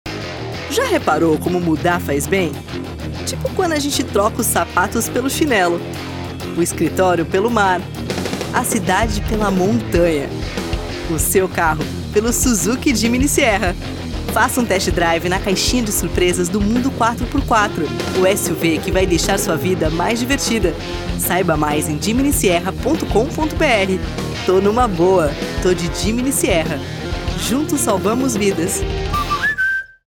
Feminino
SUZUKI (jovem, atitude)
Voz Jovem 00:30